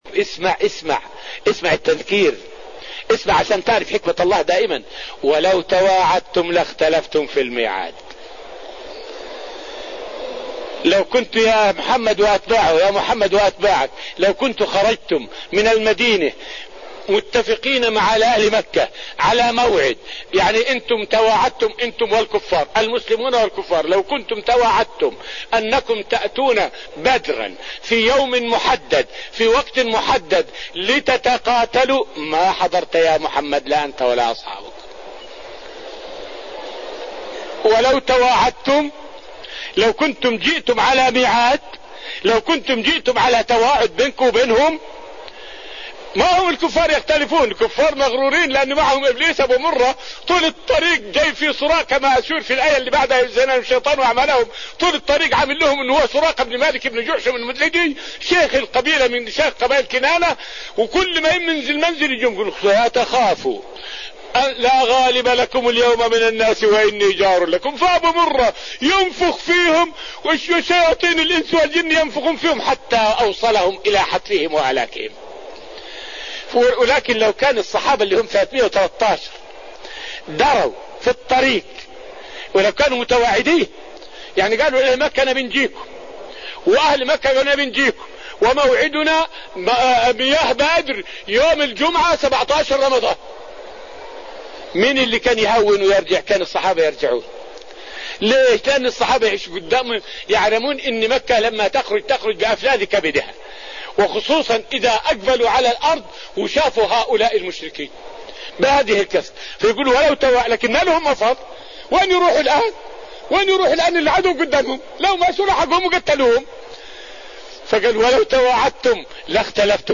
فائدة من الدرس الرابع من دروس تفسير سورة الأنفال والتي ألقيت في رحاب المسجد النبوي حول معنى قوله {ولو تواعدتم لاختلفتم في المِيعاد}.